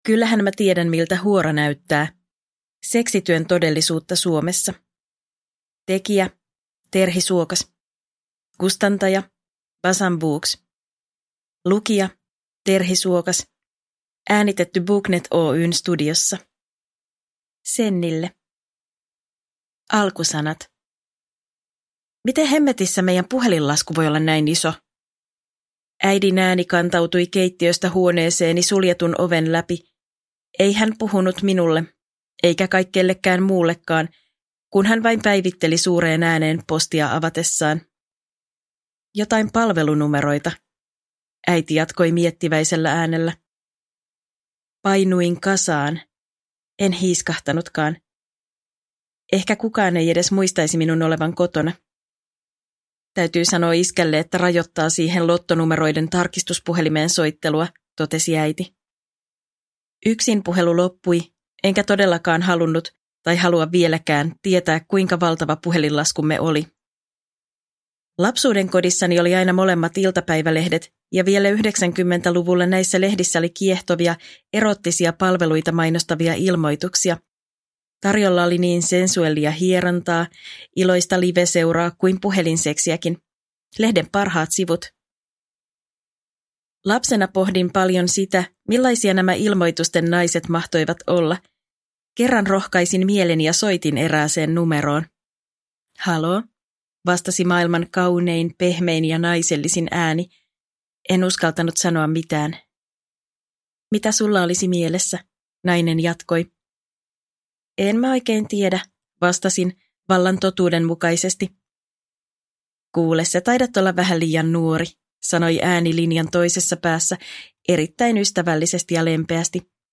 Kyllähän mä tiedän miltä huora näyttää – Ljudbok – Laddas ner